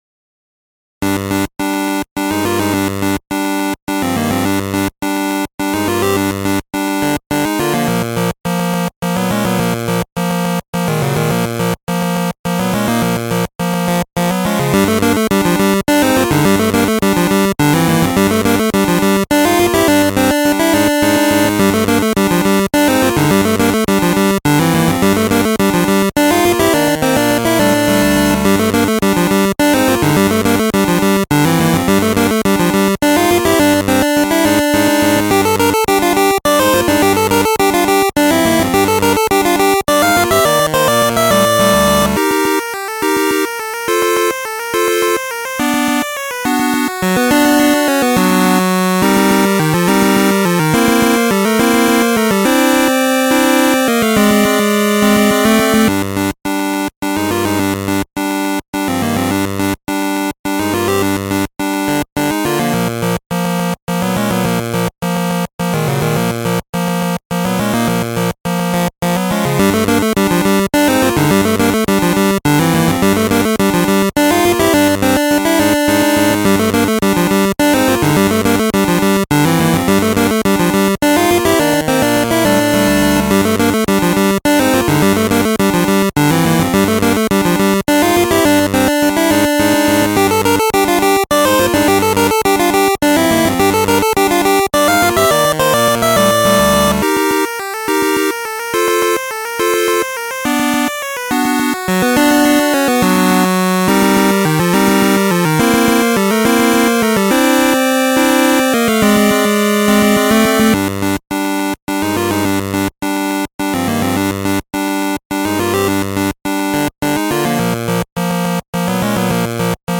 オリジナルエリア戦闘曲（パーティ／ダンジョン）です